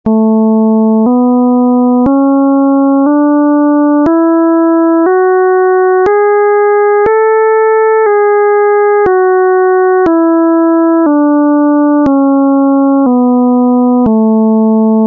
Ἡ διάρκεια τοῦ κάθε φθόγγου εἶναι 1 δευτερόλεπτο.
Νη = 220Hz
Κλίμακα Νη-Νη'
Οἱ ἤχοι ἔχουν παραχθεῖ μὲ ὑπολογιστὴ μὲ ὑπέρθεση ἀρμονικῶν.